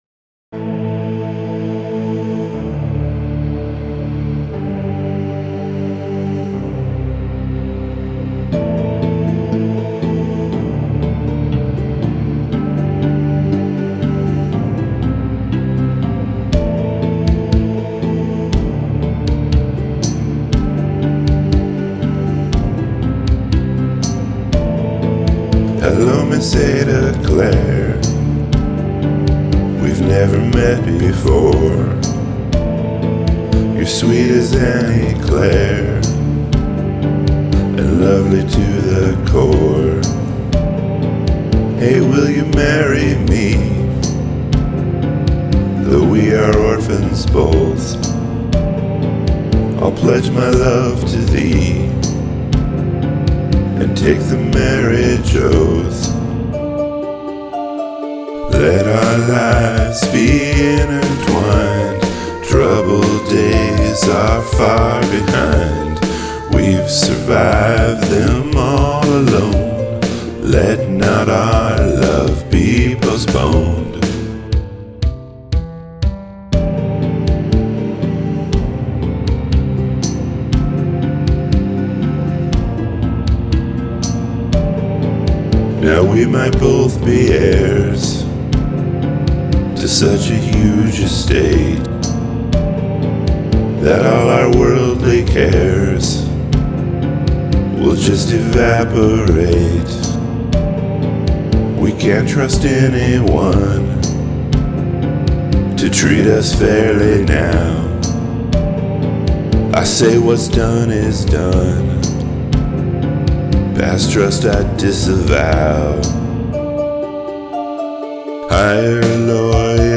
Meditative groove.
This song is chill.